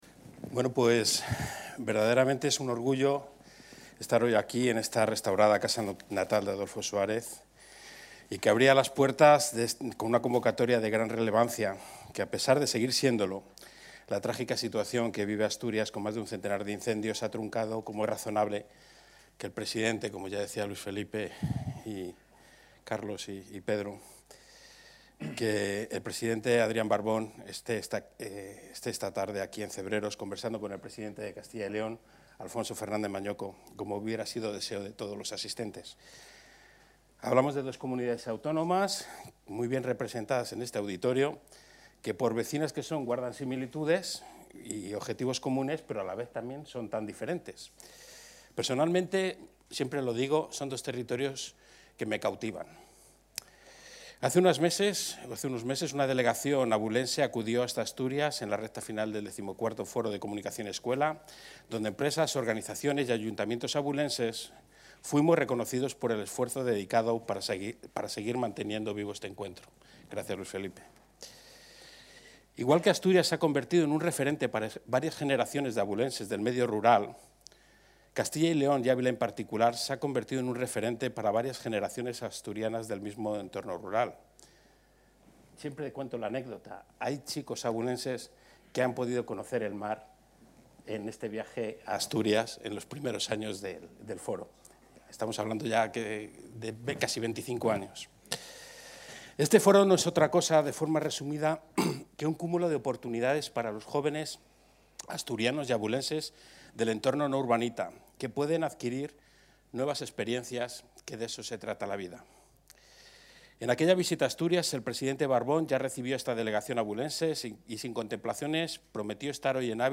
Intervención del presidente de la Junta.
El presidente de la Junta de Castilla y León, Alfonso Fernández Mañueco, ha participado esta tarde en el Foro Comunicación y Escuela, celebrado en la Casa Natal de Adolfo Suárez, en Cebreros, Ávila